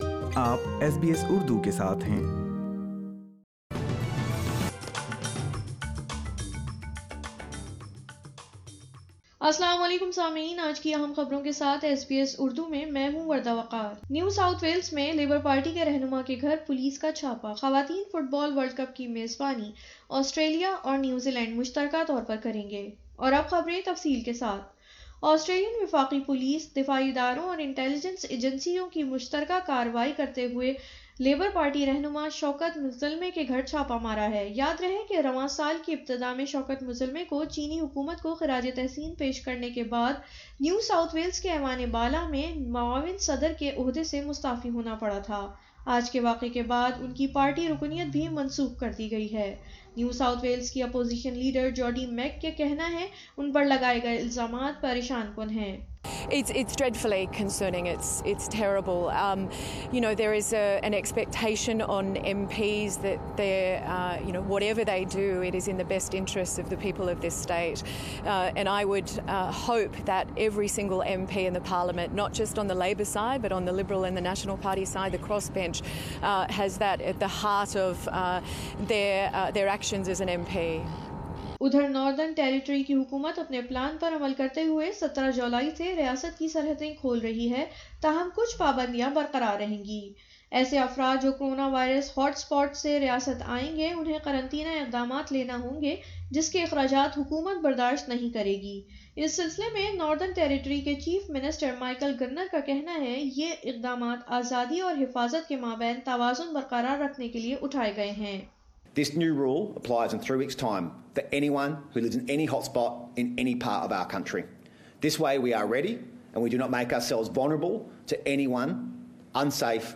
اردو خبریں 26 جون 2020